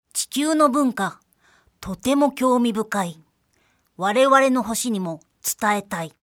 ボイス
キュート女性